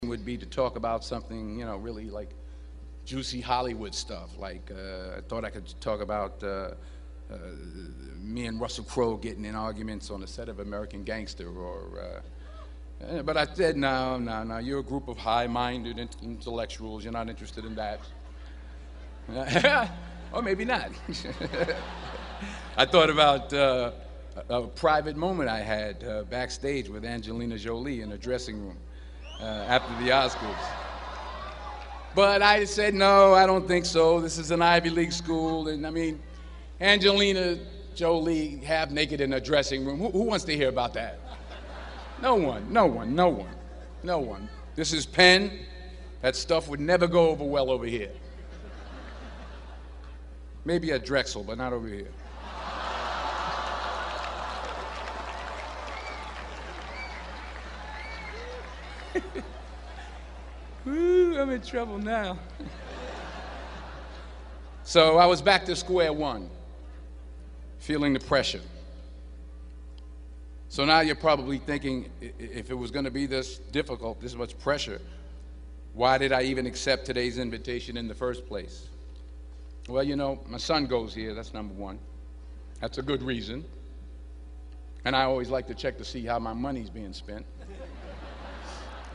公众人物毕业演讲第420期:丹泽尔2011宾夕法尼亚大学(4) 听力文件下载—在线英语听力室